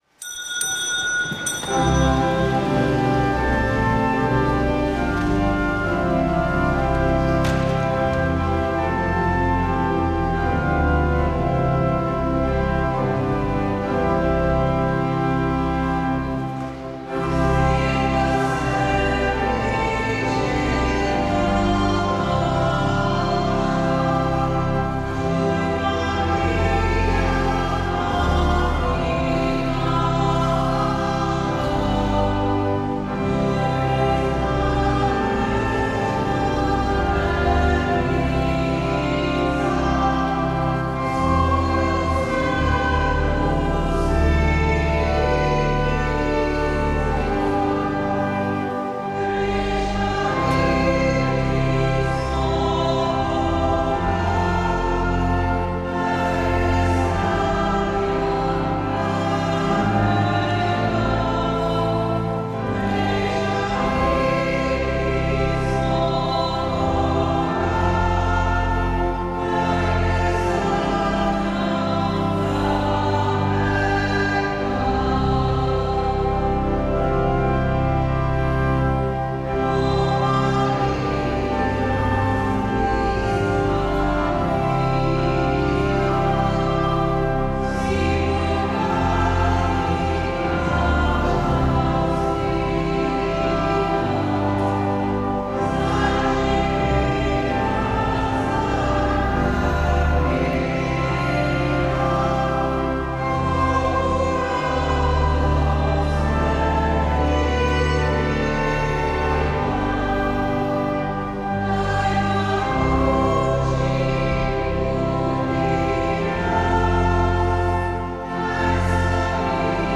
Sveta maša
Sv. maša iz župnijske cerkve sv. Jožefa in sv. Barbare iz Idrije 3. 11.